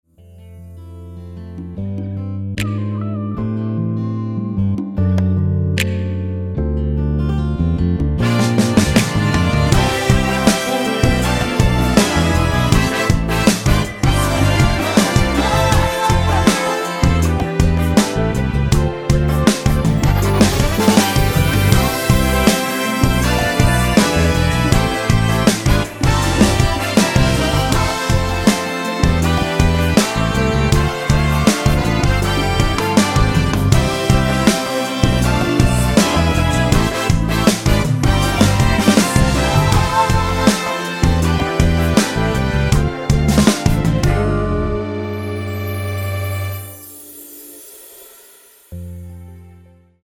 처음 시작 보컬 부분은 코러스가 아니기 때문에 직접 노래를 하셔야 됩니다.
원키에서(-1)내린 코러스 포함된 MR 입니다.(미리듣기 참조)
앞부분30초, 뒷부분30초씩 편집해서 올려 드리고 있습니다.